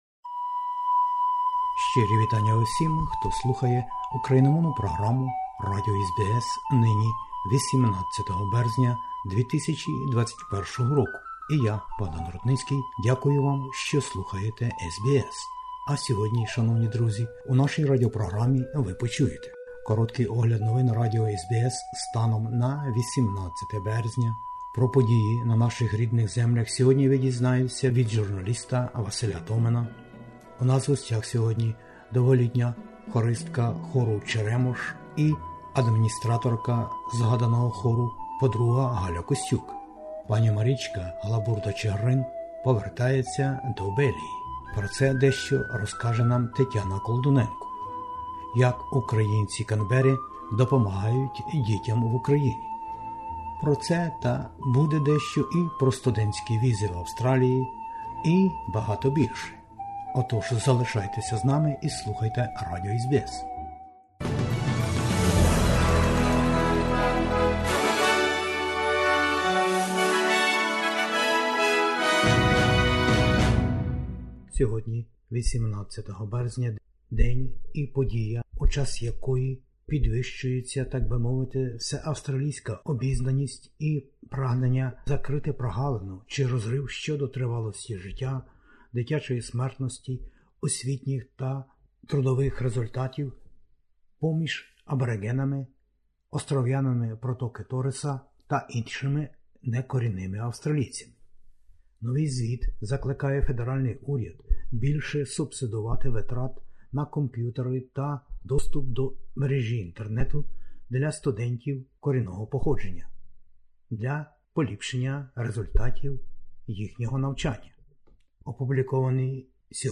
SBS НОВИНИ УКРАЇНСЬКОЮ
SBS Ukrainian, 3 pm FM, TV Ch. 38 and 302, every Thursday Source: SBS